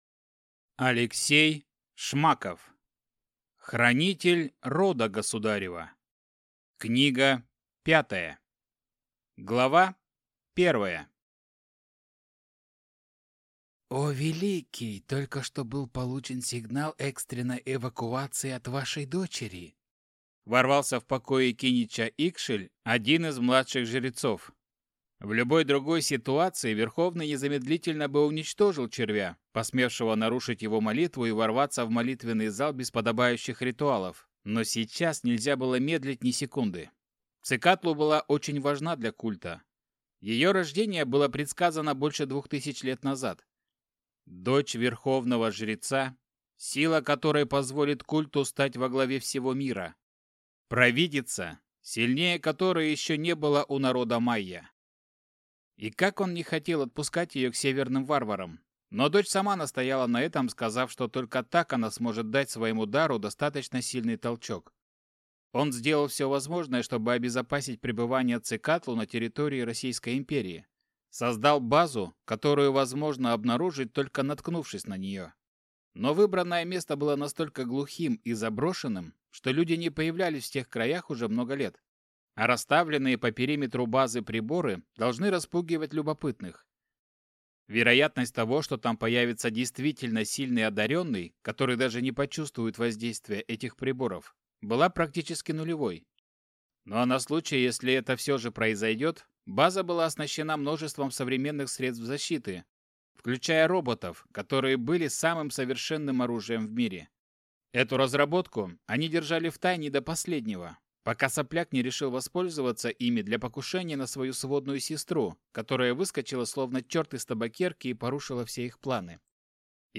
Аудиокнига Хранитель рода государева 5 | Библиотека аудиокниг
Прослушать и бесплатно скачать фрагмент аудиокниги